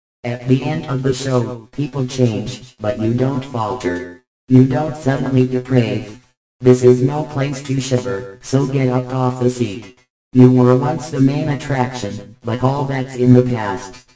(96.8KB) Output from Text-to-Speech with SFX added.